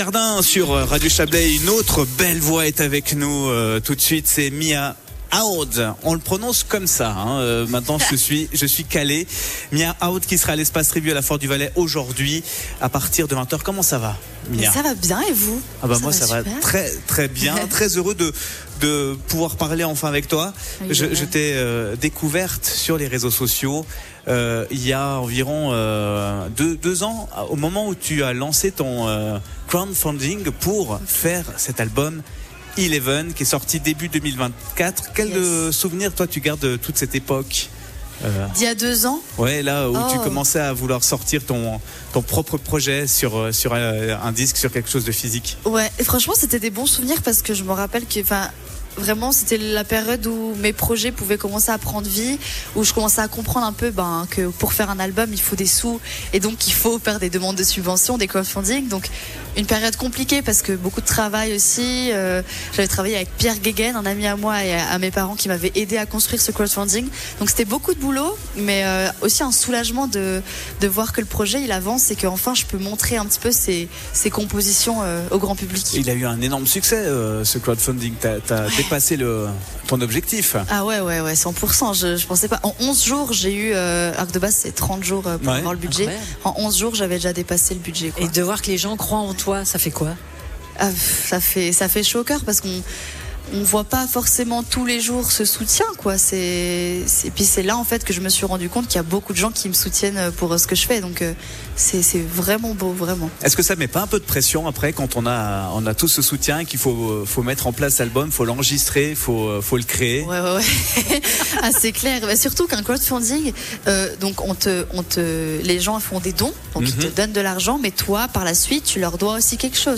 sur le stand de la radio